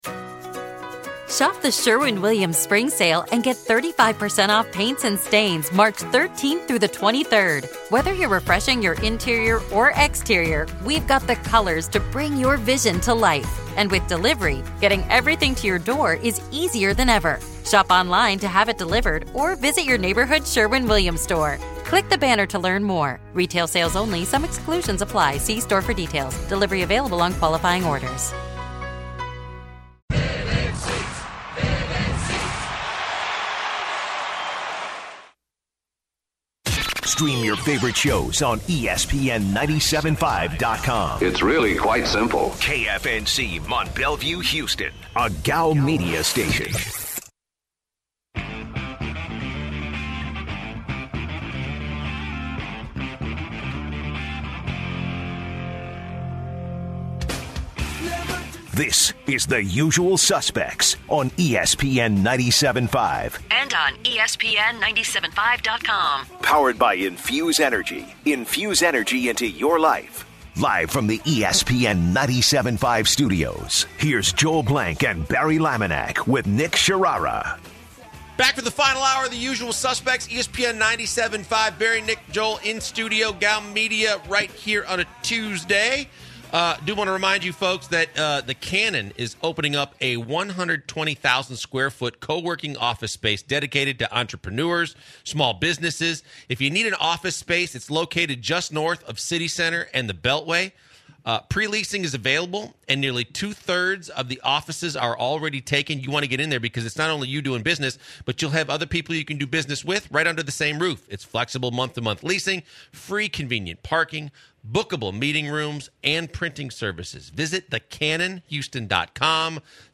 For the last hour, the guys start off going over the upcoming NBA games then pivot to the NFL to discuss the Texans, players’ signings, and where unclaimed players can land. At the end of the hour, a caller poses an interesting question to them.